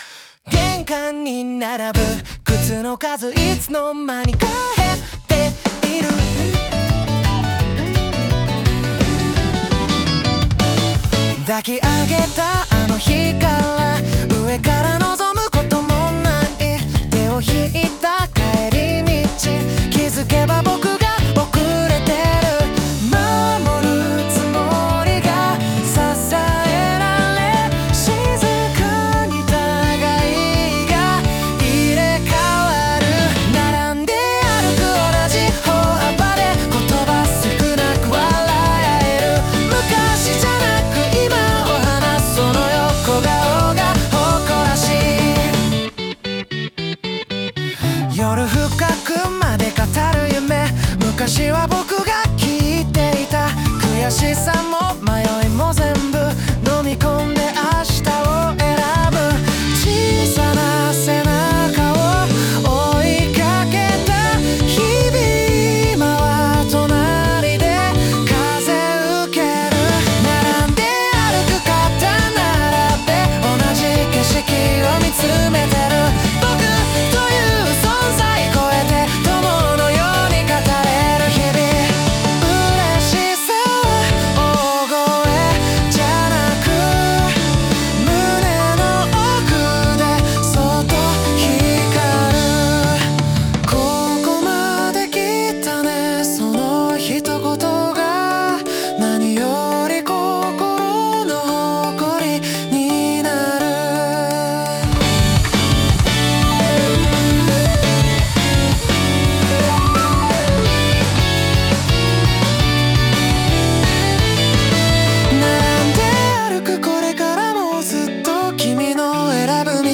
男性ボーカル
イメージ：J-POP,男性ボーカル,優しい,幸せ,前向き,シンコペーションギター,マスロック